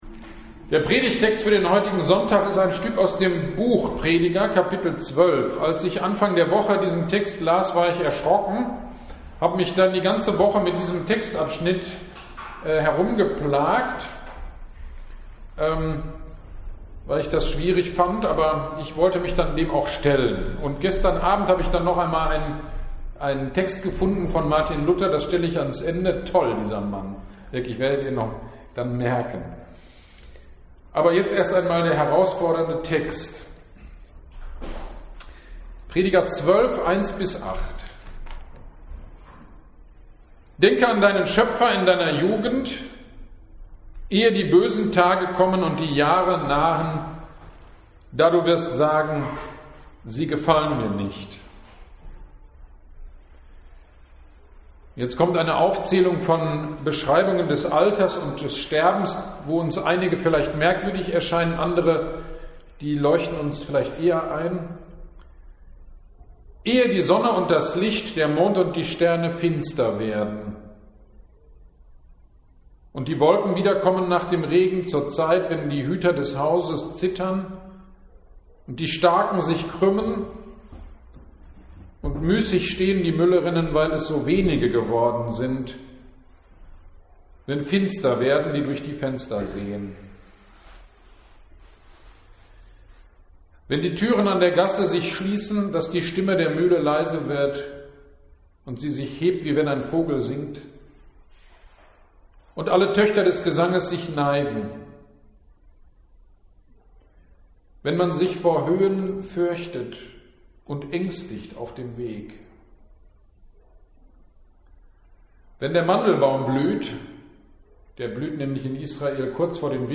Predigt über Prediger 12,1-8 - Kirchgemeinde Pölzig
Predigt über Prediger 12,1-8